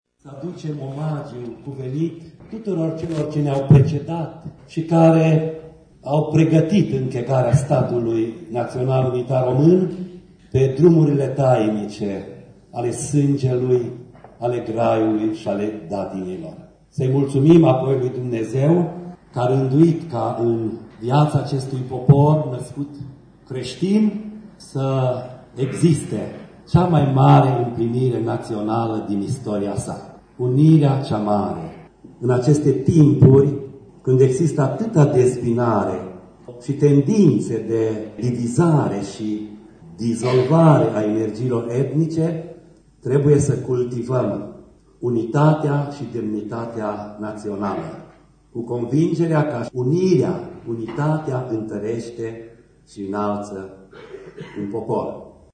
Arhiepiscopul Ortodox de Alba Iulia, ÎPS Irineu, a transmis un mesaj la deschiderea lucrărilor Congresului: